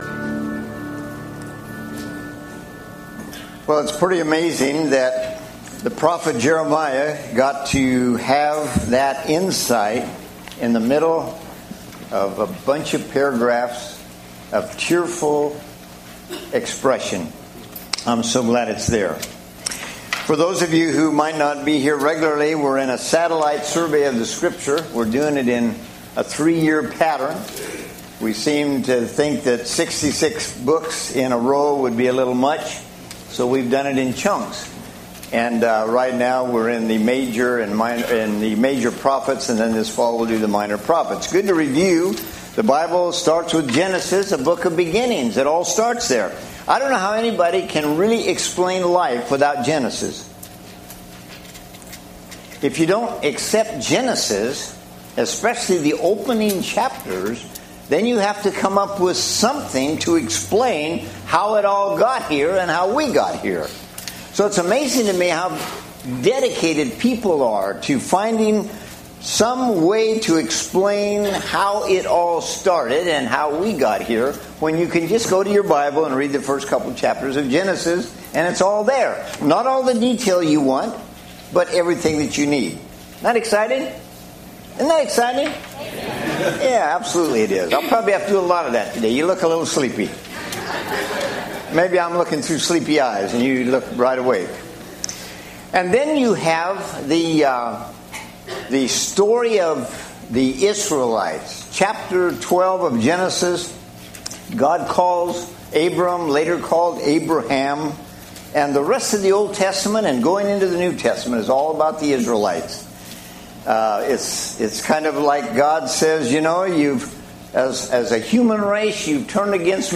Sermon-5-29-16.mp3